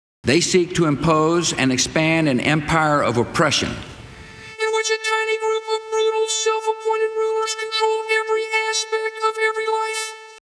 Try 440Hz sawtooth instead of sine. Also if you want clear speech, try a high-resolution vocoder plugin e.g. mda talkbox